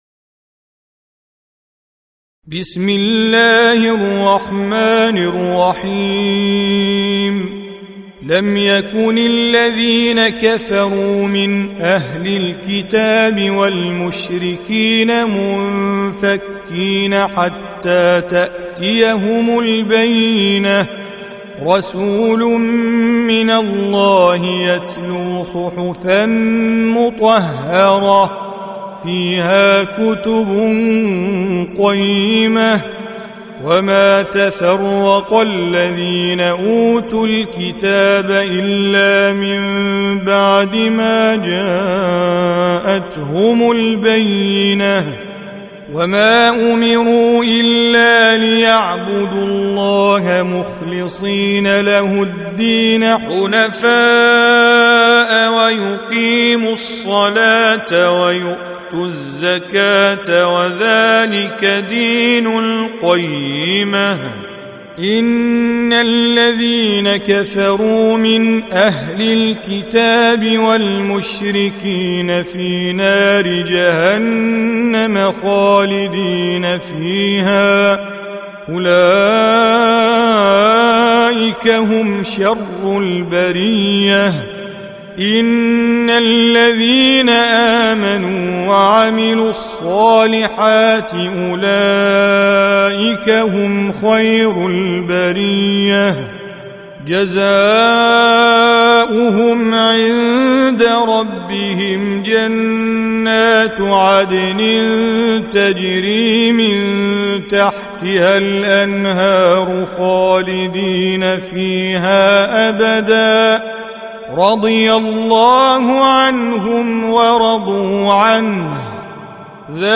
Surah Repeating تكرار السورة Download Surah حمّل السورة Reciting Mujawwadah Audio for 98. Surah Al-Baiyinah سورة البينة N.B *Surah Includes Al-Basmalah Reciters Sequents تتابع التلاوات Reciters Repeats تكرار التلاوات